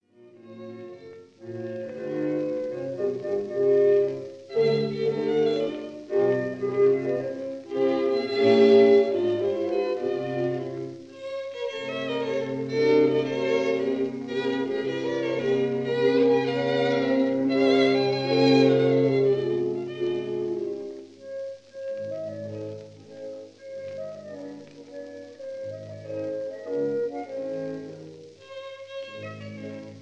clarinet
bassoon
horn
violins
viola
cello
double-bass
Recorded in Société suisse de radiodiffusion
studio, Geneva in July 1948